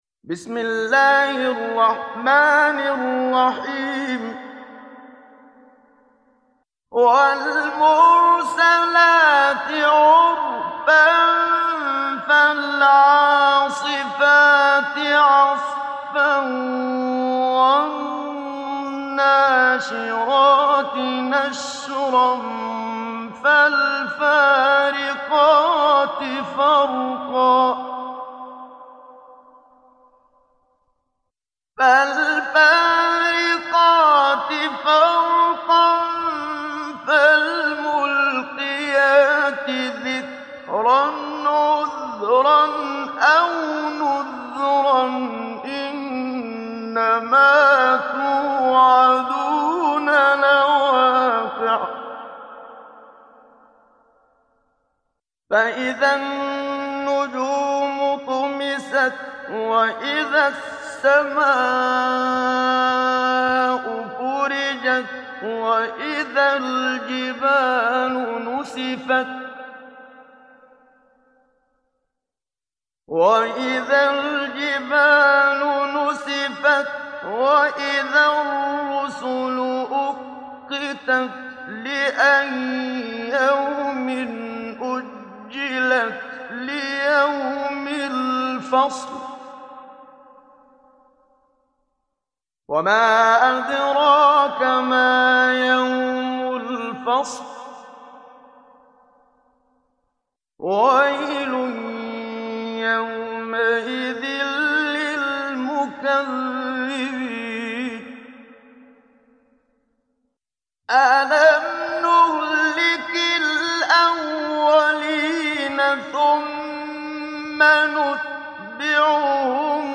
Surat Al Mursalat Download mp3 Muhammad Siddiq Minshawi Mujawwad Riwayat Hafs dari Asim, Download Quran dan mendengarkan mp3 tautan langsung penuh